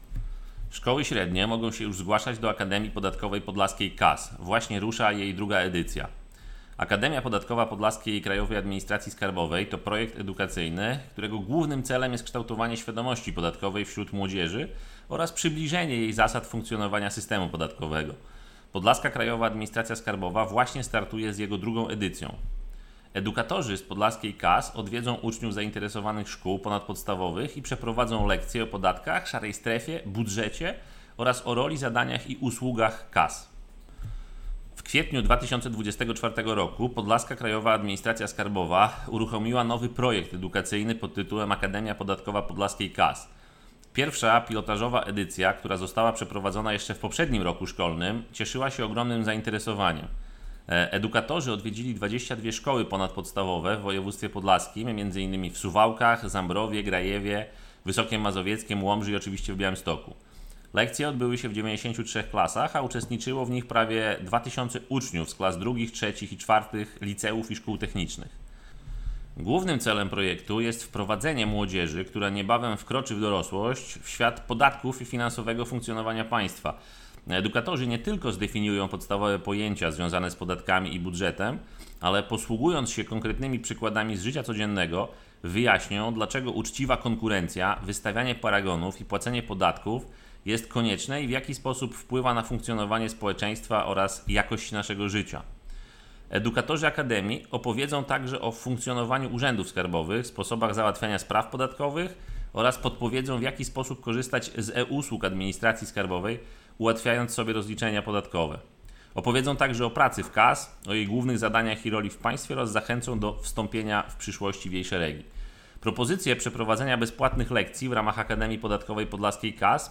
II edycja Akademii Podatkowej Podlaskiej KAS (wypowiedź